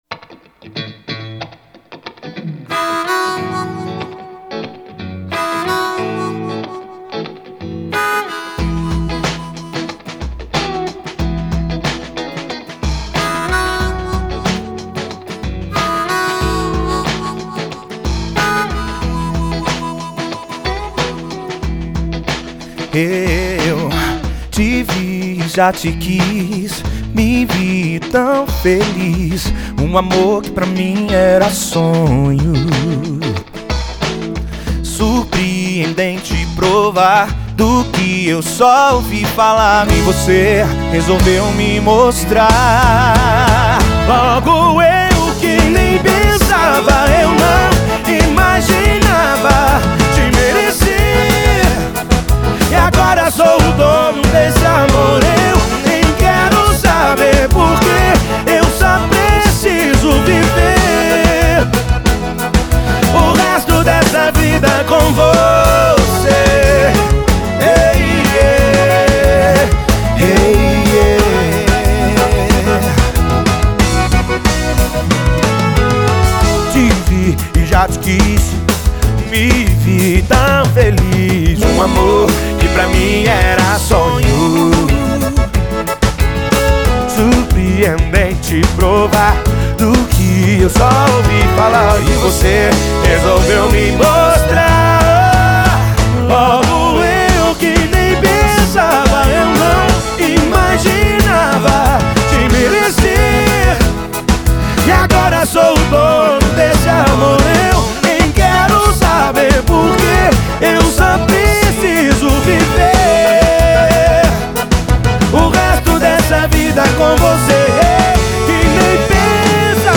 2025-04-09 11:52:46 Gênero: Sertanejo Views